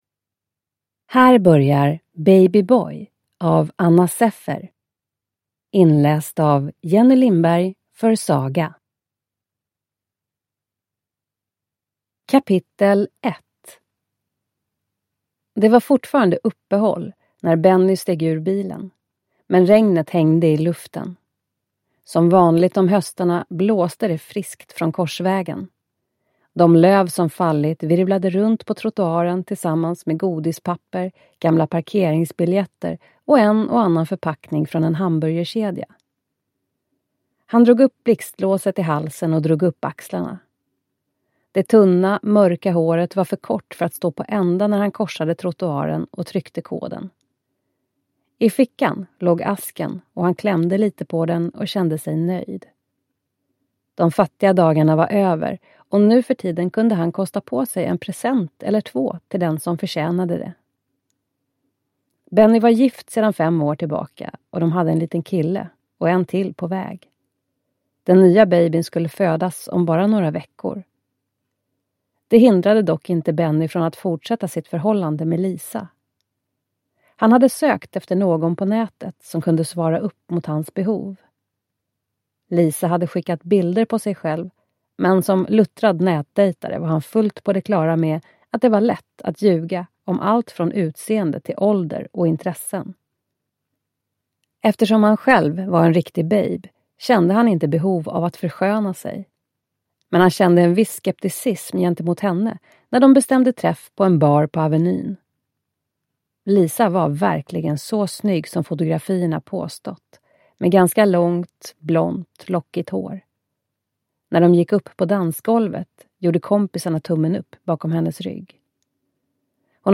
Baby Boy / Ljudbok